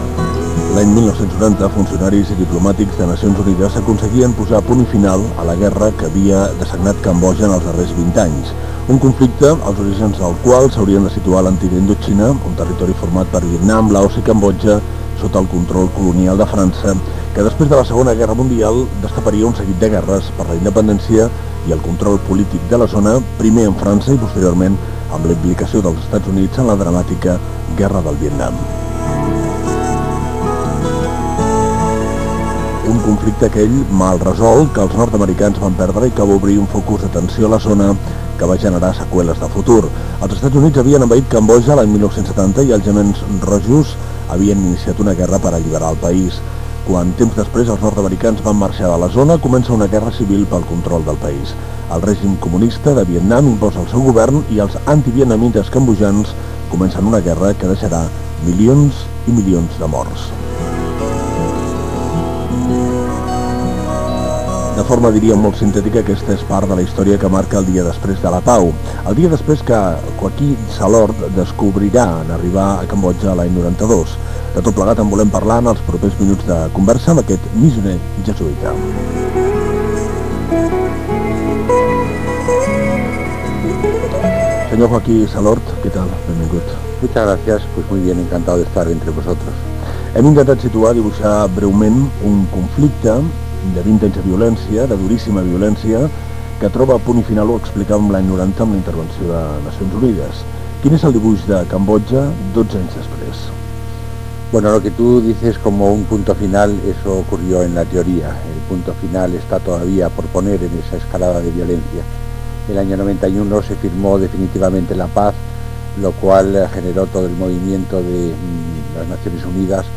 Entrevista a un misioner jesuïta que ha estat a Cambodja